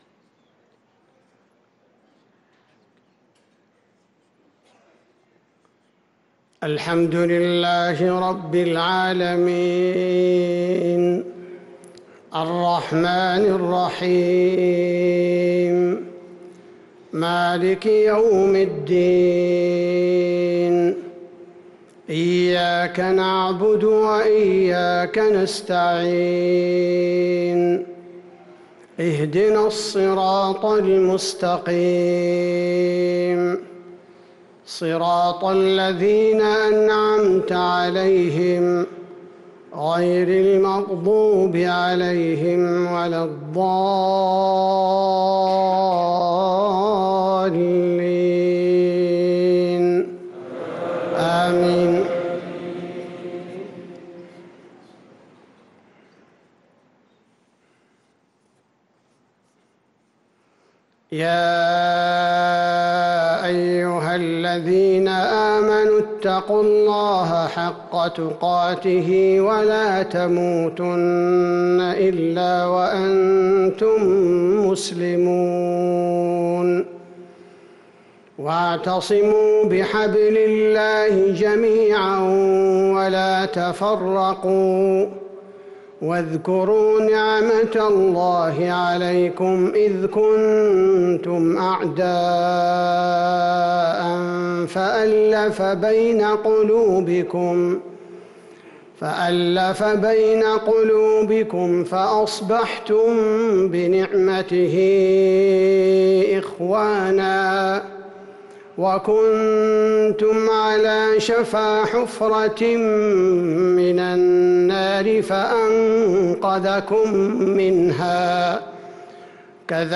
صلاة العشاء للقارئ عبدالباري الثبيتي 3 جمادي الآخر 1445 هـ
تِلَاوَات الْحَرَمَيْن .